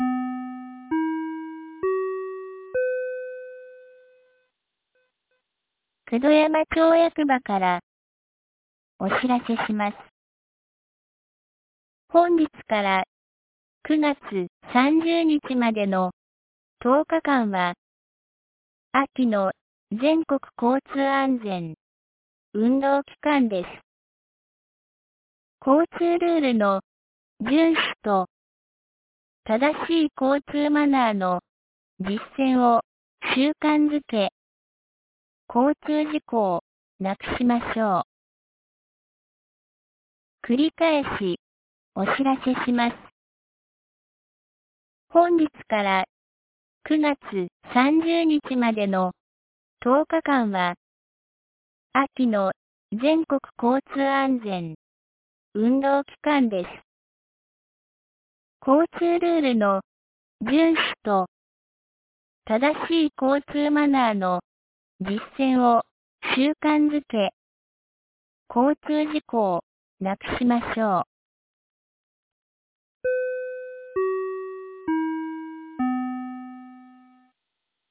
2025年09月21日 12時06分に、九度山町より全地区へ放送がありました。